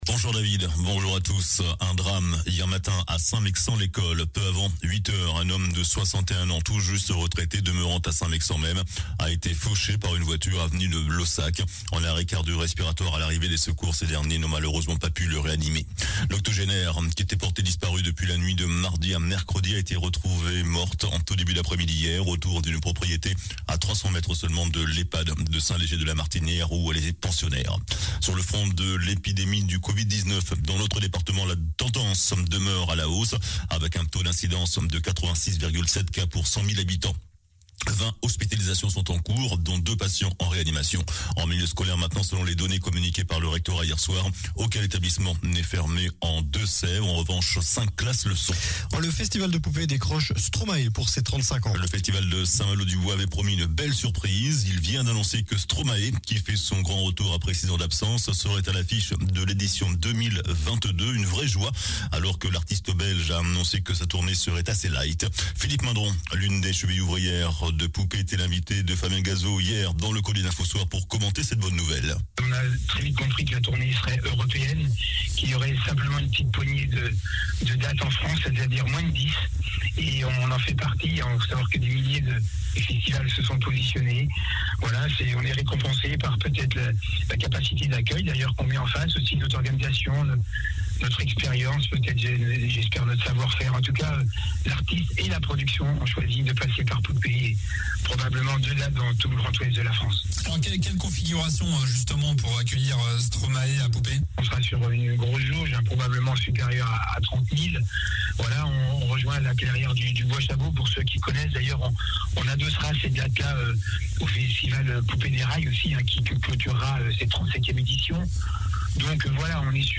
JOURNAL DU SAMEDI 23 OCTOBRE